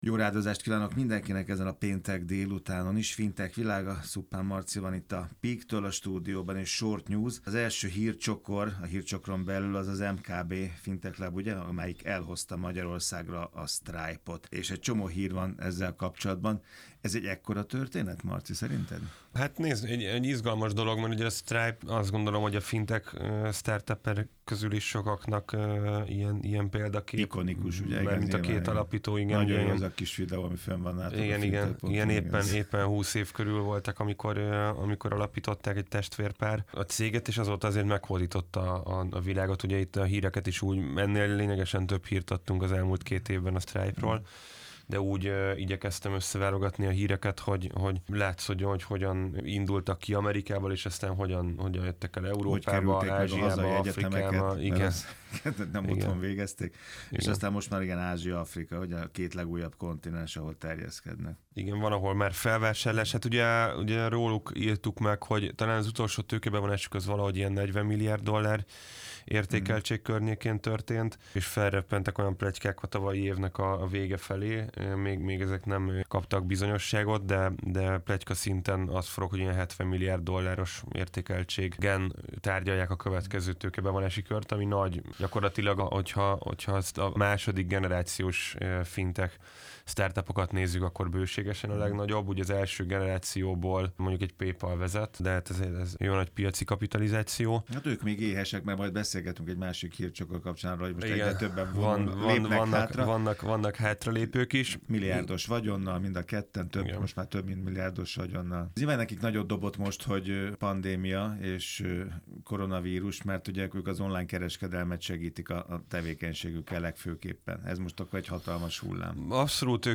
A mai Fintech Világa rádióműsorban